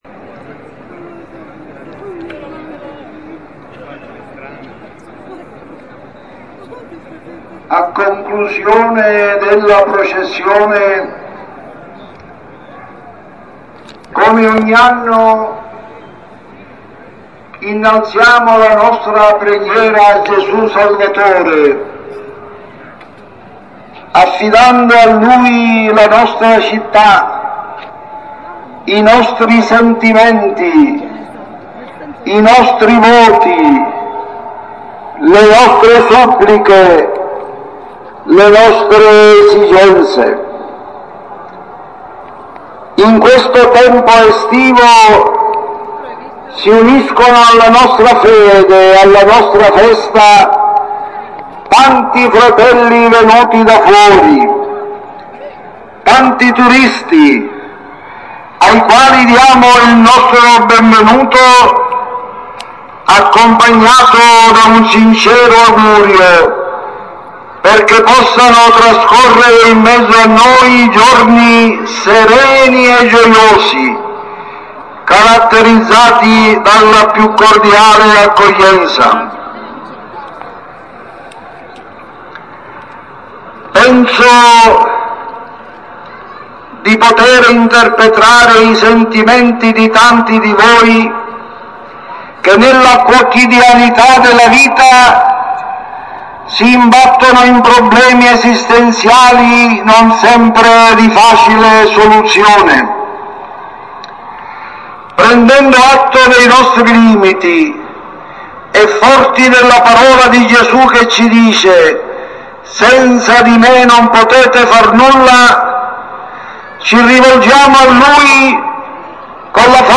AUDIO: La Predica del Vescovo alla fine della Processione